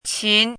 chinese-voice - 汉字语音库
qin2.mp3